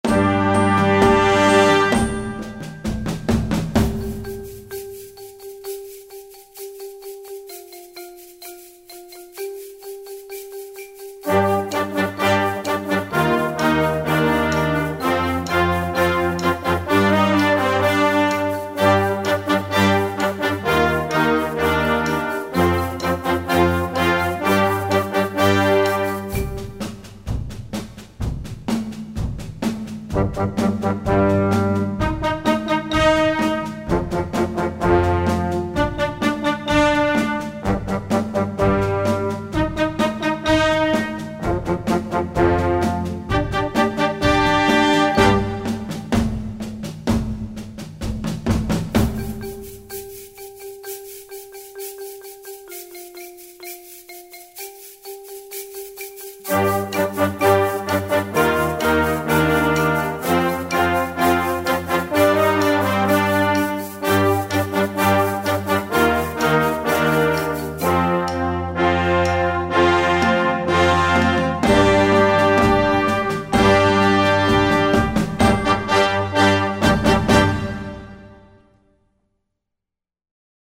Gattung: Kleines Konzertstück für Jugendblasorchester
Besetzung: Blasorchester
schwungvolle und zeitgemäße Werk